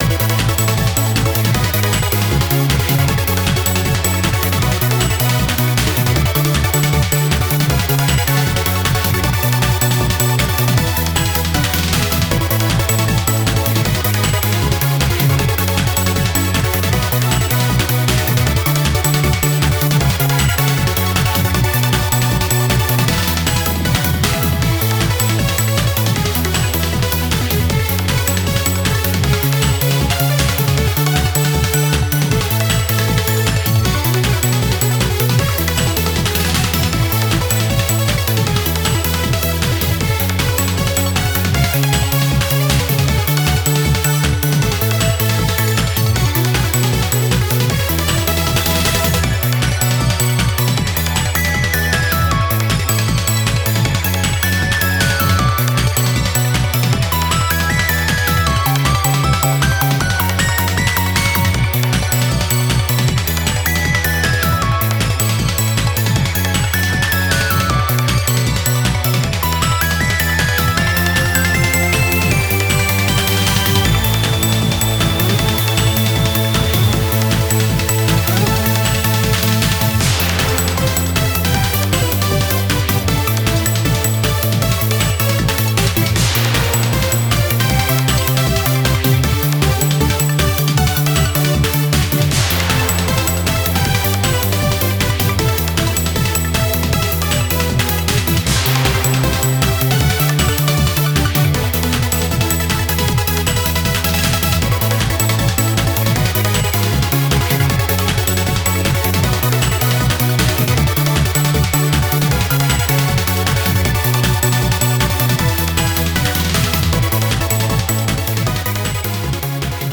BPM156
MP3品質Music Cut